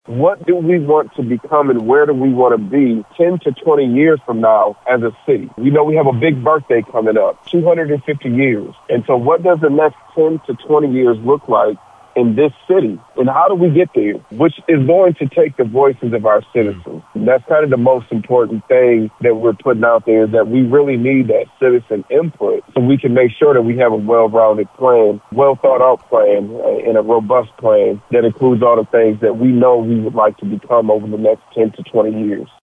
Jackson Mayor Daniel Mahoney discussed the overall goal of the plan being to help shape the future of the city, with citizen input playing a critical role.